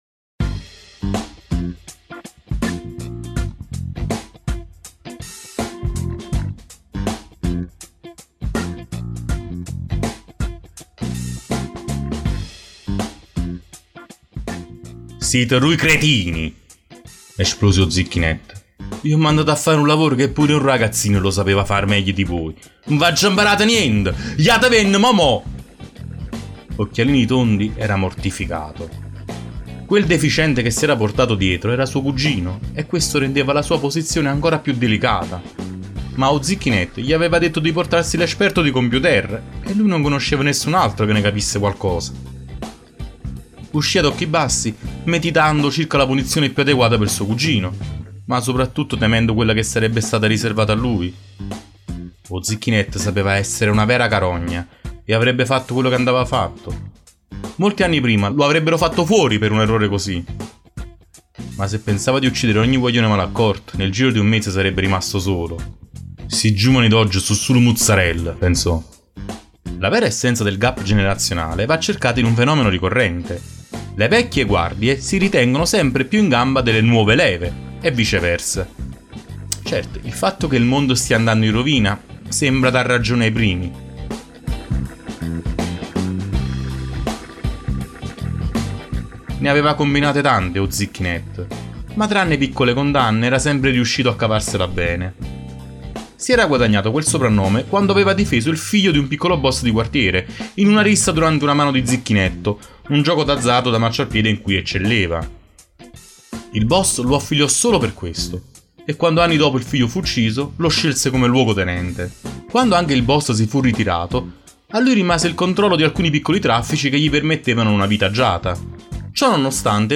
interpretato da